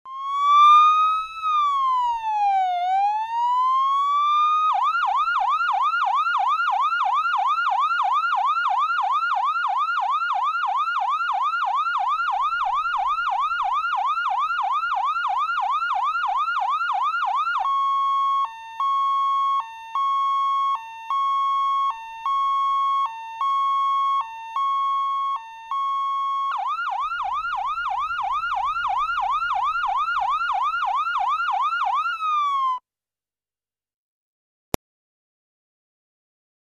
AMBULANCIA AMBULANCIA AMBULANCIA
Ambient sound effects
Descargar EFECTO DE SONIDO DE AMBIENTE AMBULANCIA AMBULANCIA AMBULANCIA - Tono móvil
Ambulancia_ambulancia_ambulancia.mp3